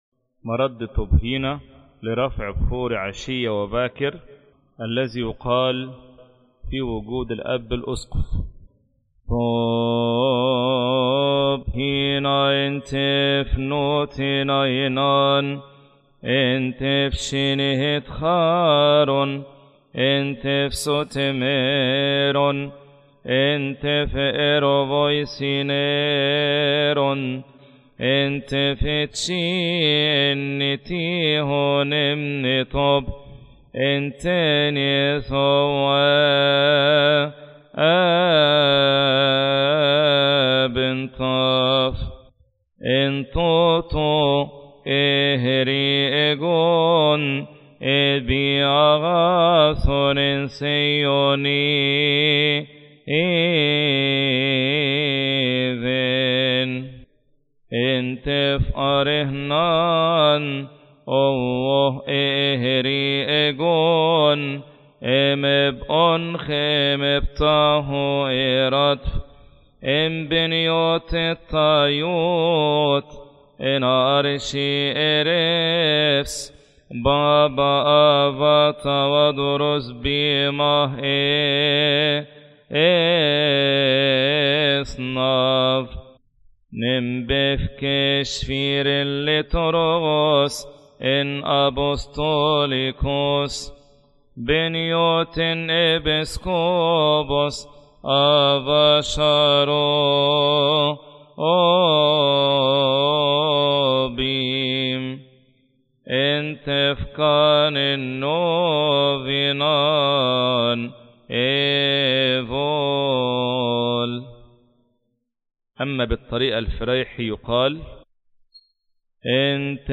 مكتبة الألحان